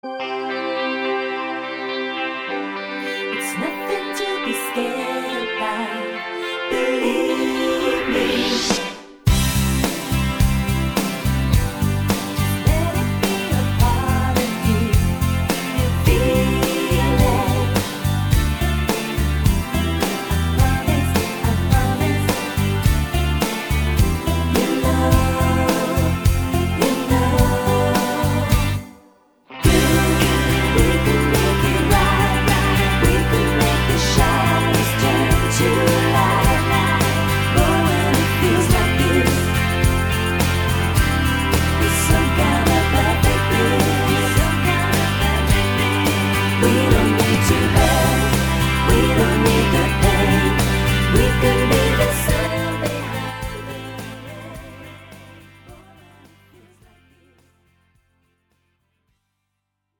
팝송